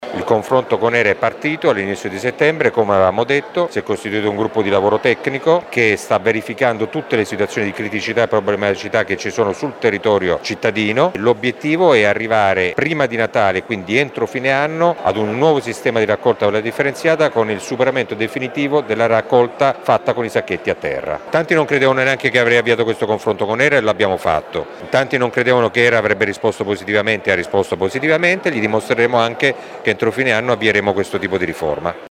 Il sindaco di Modena Massimo Mezzetti: